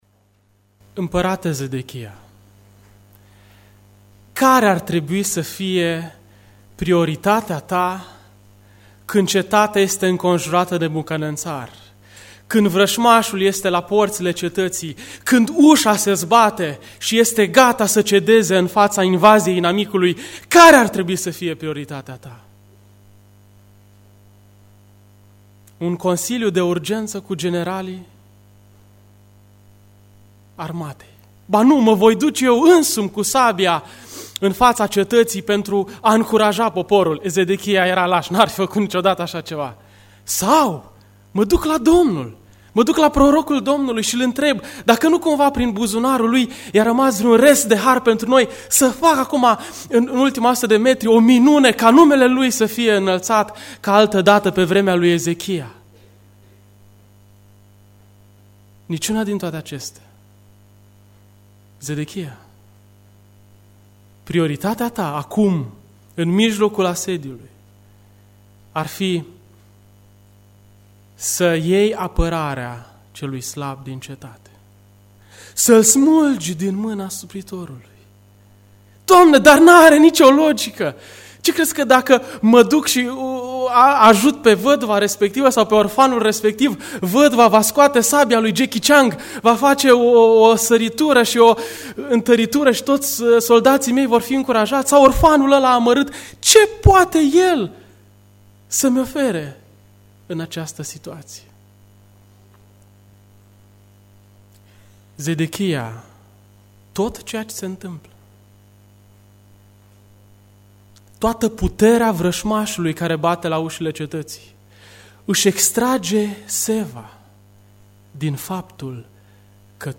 Predica Aplicatie - Ieremia cap. 21-23a